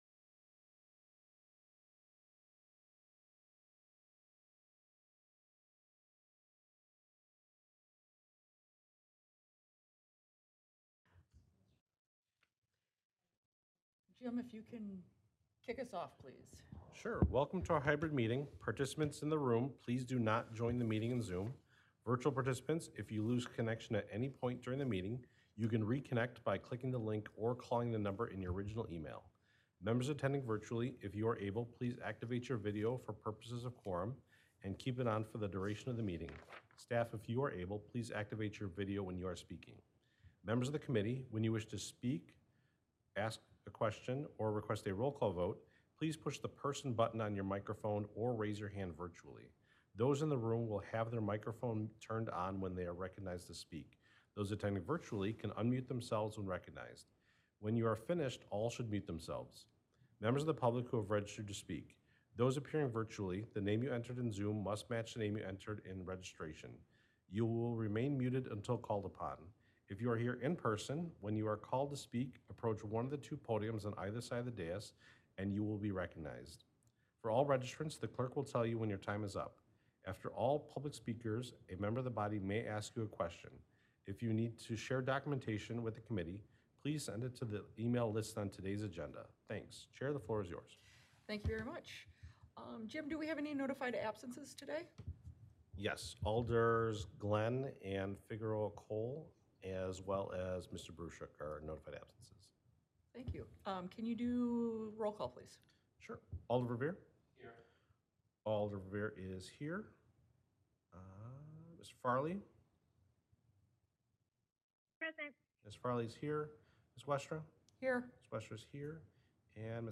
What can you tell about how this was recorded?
This is a podcast of City of Madison, Wisconsin Alcohol License Review Committee meetings.